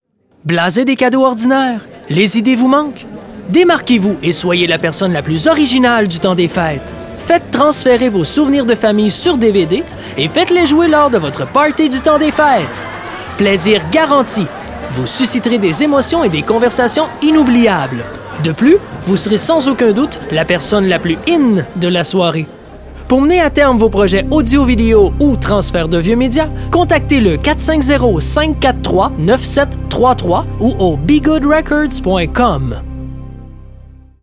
Publicité Radiophonique                               Messages téléphonique